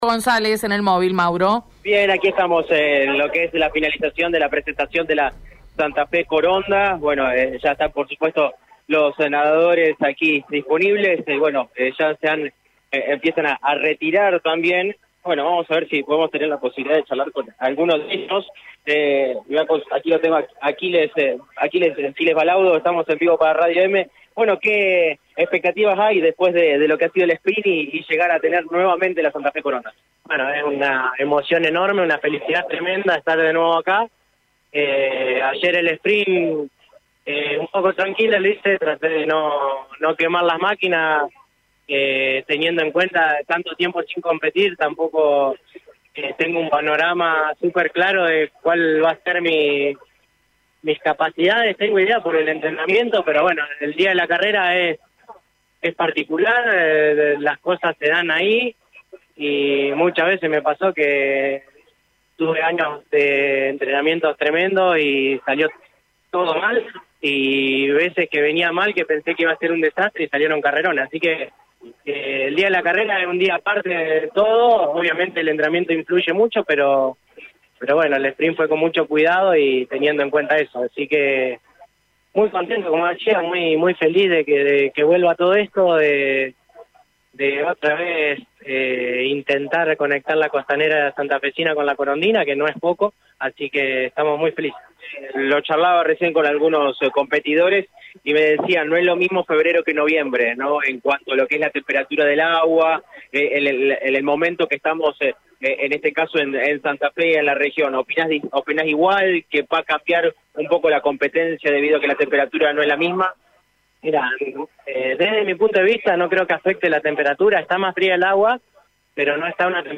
La Santa Fe-Coronada Fue presentada este viernes en un acto en el Salón Blanco de la Casa de Gobierno.